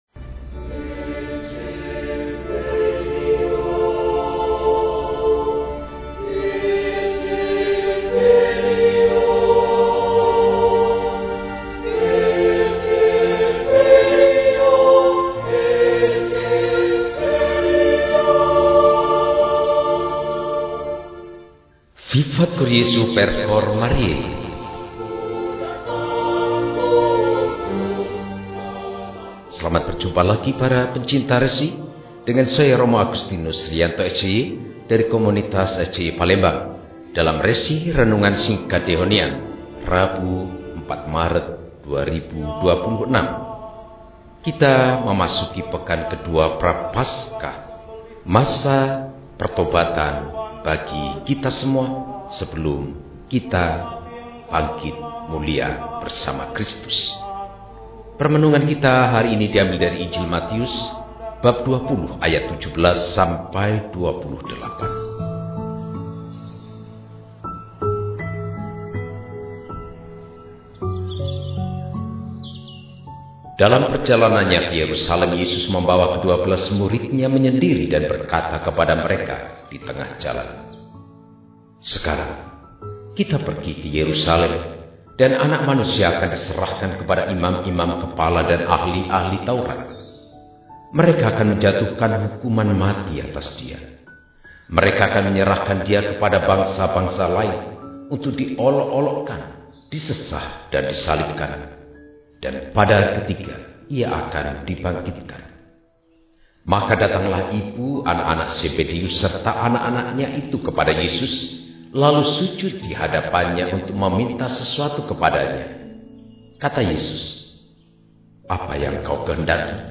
Rabu, 04 Maret 2026 – Hari Biasa Pekan II Prapaskah – RESI (Renungan Singkat) DEHONIAN